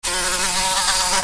دانلود صدای حشره 11 از ساعد نیوز با لینک مستقیم و کیفیت بالا
جلوه های صوتی
برچسب: دانلود آهنگ های افکت صوتی انسان و موجودات زنده دانلود آلبوم صدای انواع حشرات از افکت صوتی انسان و موجودات زنده